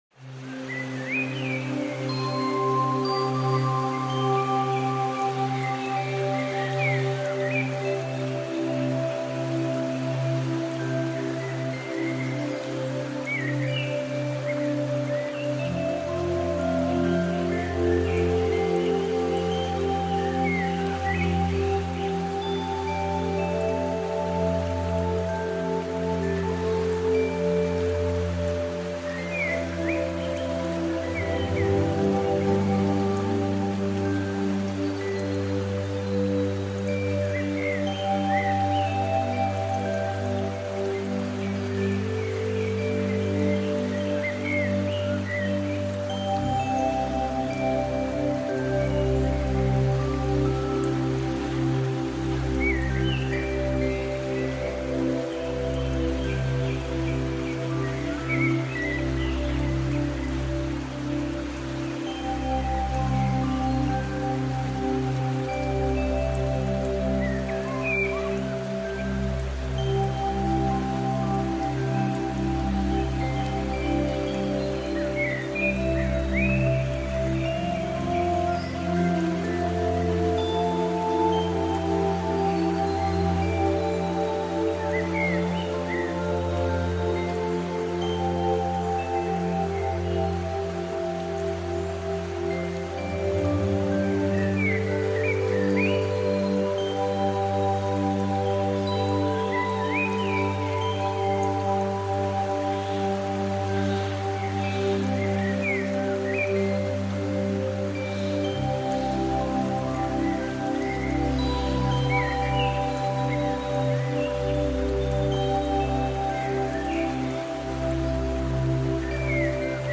موسیقی طولانی ملایم